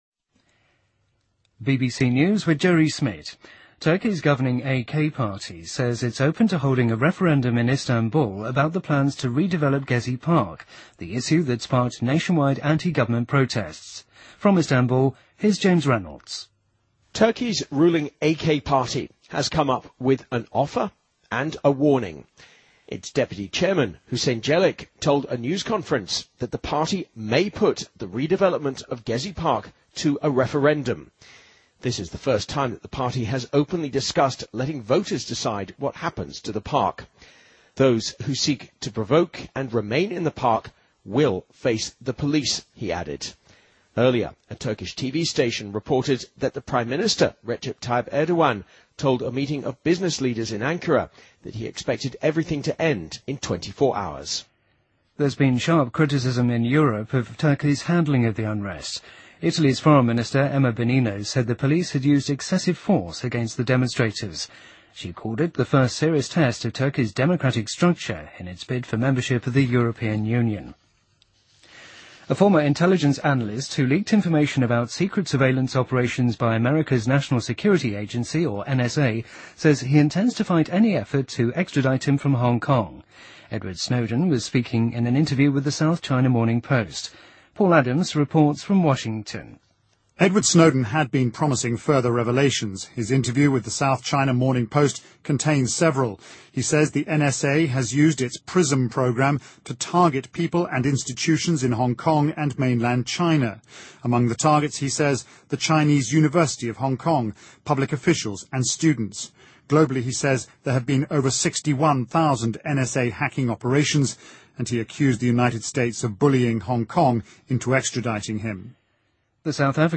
BBC news,2013-06-13